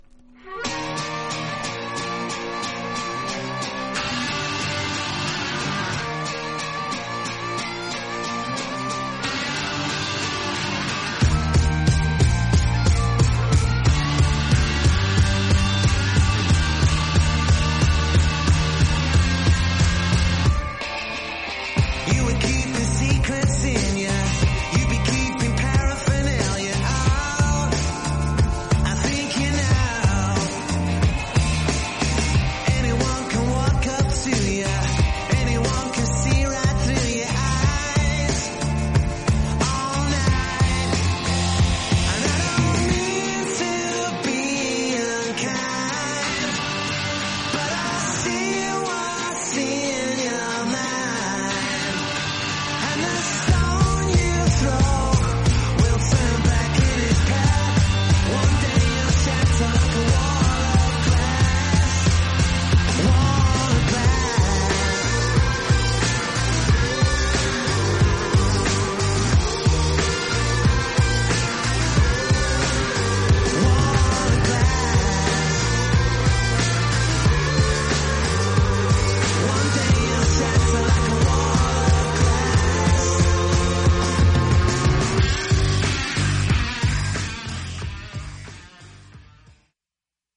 盤面薄いスレが少しだけありますが音に影響ありません。
実際のレコードからのサンプル↓ 試聴はこちら： サンプル≪mp3≫